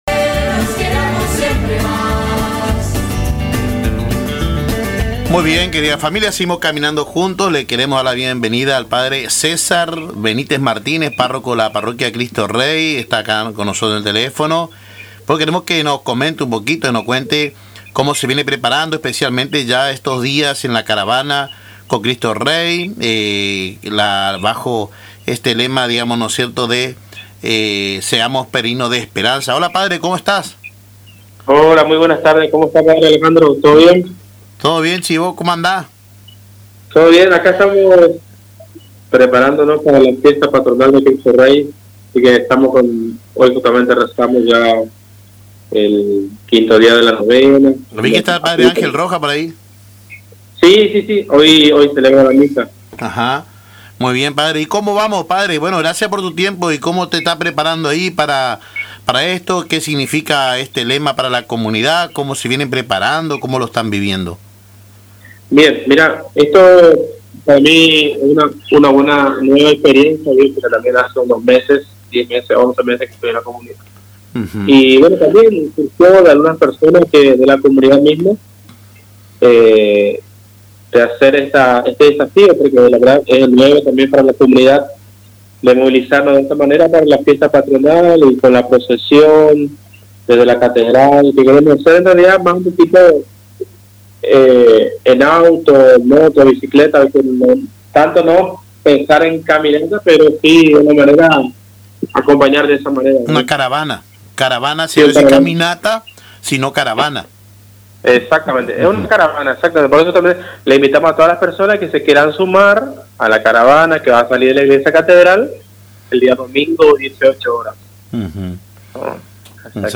La comunidad de la parroquia Cristo Rey se encuentra en los últimos días de preparación para su fiesta patronal, con una serie de actividades que buscan unir y fortalecer los lazos vecinales y espirituales. En diálogo con Caminando Juntos por Radio Tupambaé